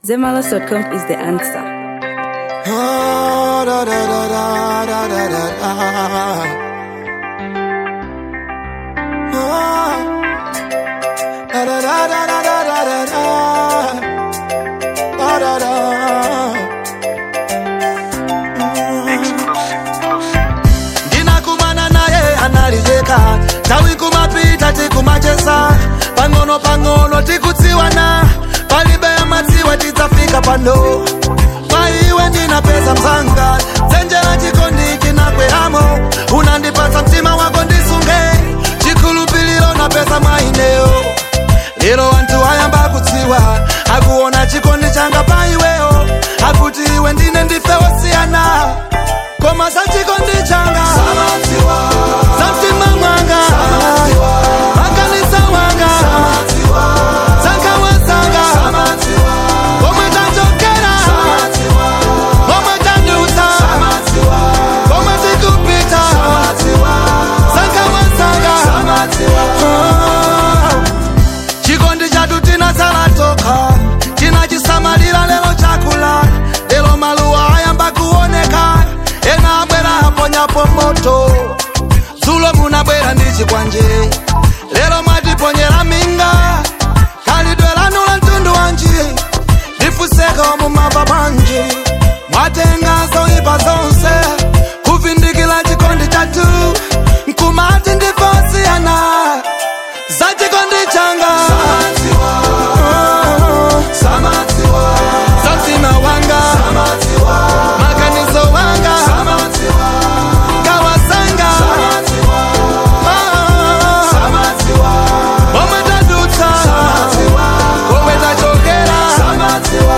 Fusion • 2025-10-27